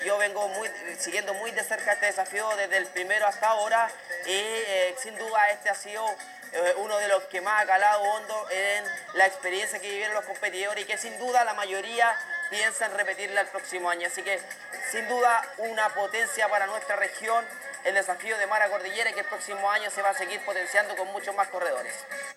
consejero-regional-darwin-Ibacache.mp3